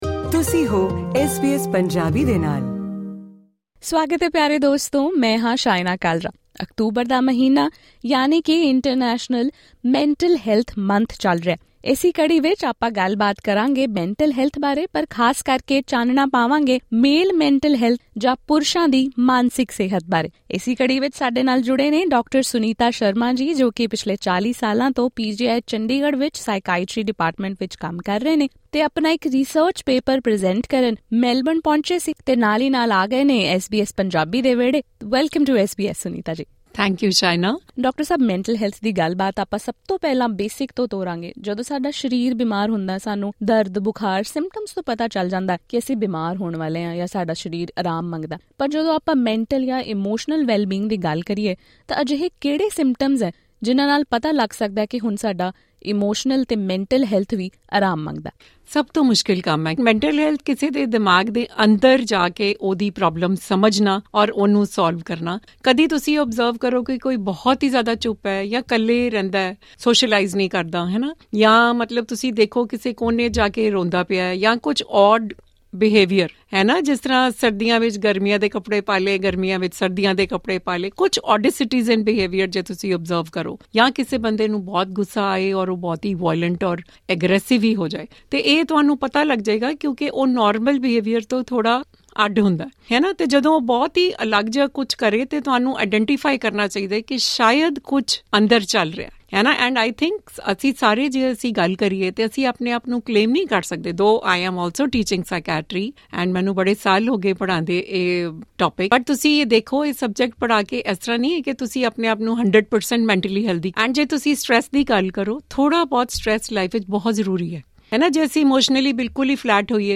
October is celebrated as mental health month. This month, let's discuss about mental health among men, especially south Asian men. Listen to this conversation